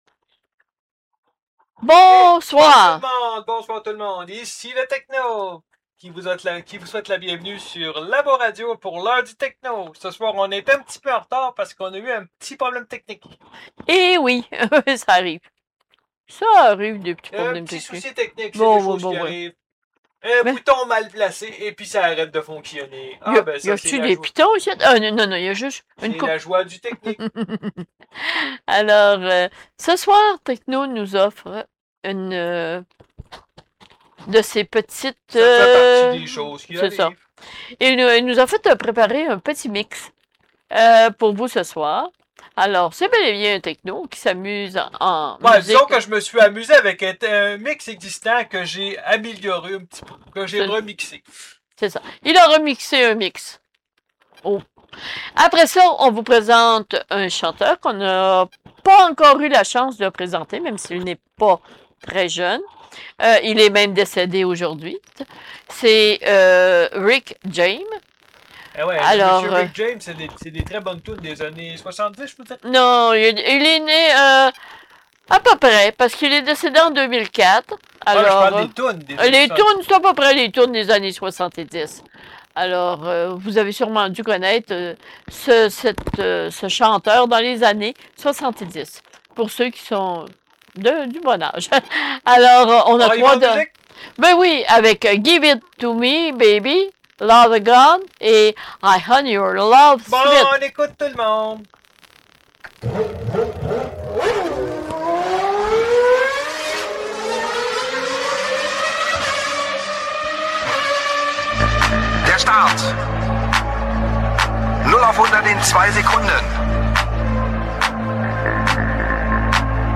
rediffusion